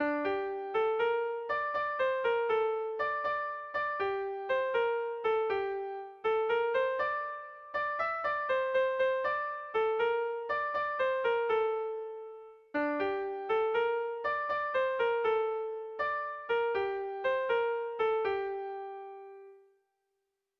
Air de bertsos - Voir fiche   Pour savoir plus sur cette section
Irrizkoa
ABDAB2